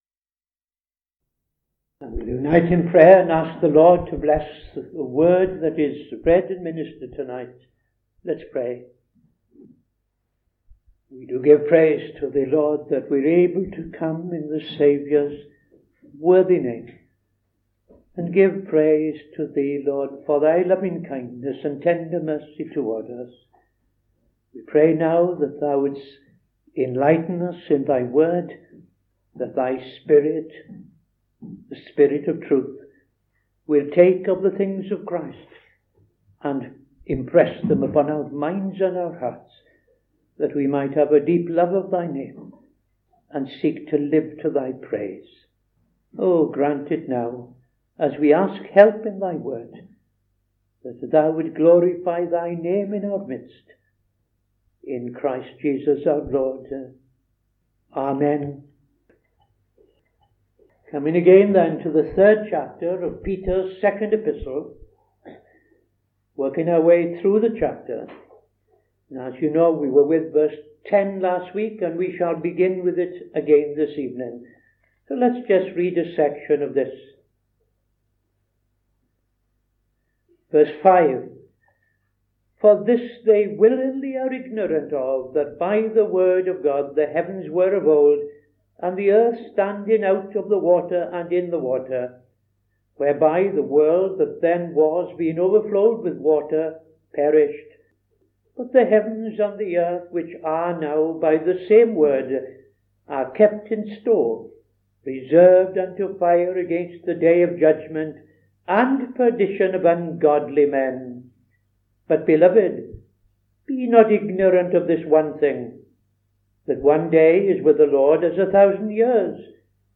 Saturday Sermon - TFCChurch
Opening Prayer and Reading II Peter 3:5-12